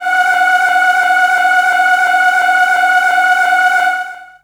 55be-syn17-f#4.wav